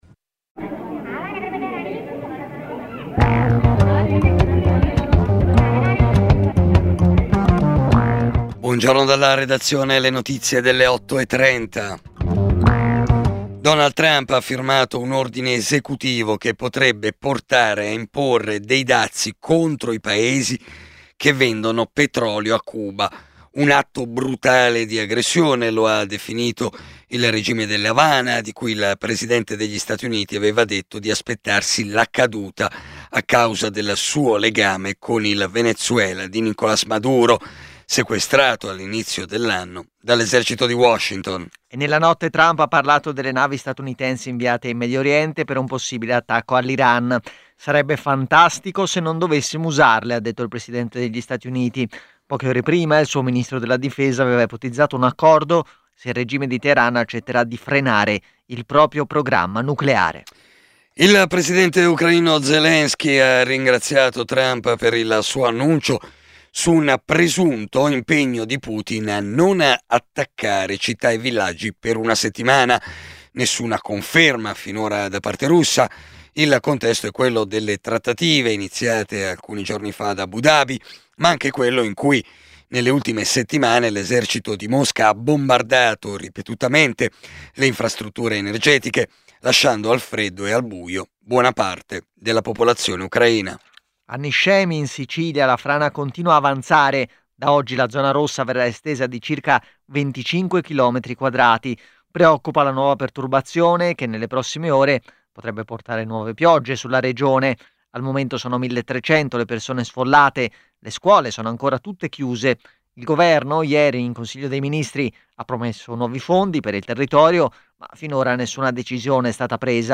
Edizione breve del notiziario di Radio Popolare.